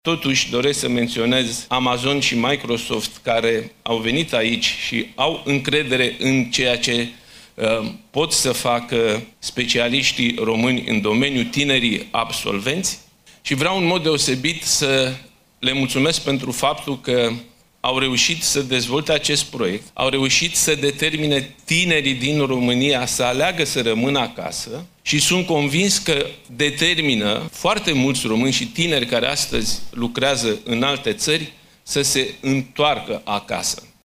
Cea mai mare clădire de birouri din România, Palas Campus, a fost inaugurată astăzi, la Iaşi, în prezenţa premierului Nicolae Ciucă şi a ambasadorului SUA la Bucureşti, Kathleen Kavalec.